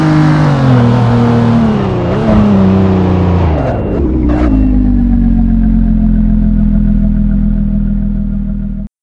slowdown_highspeed.wav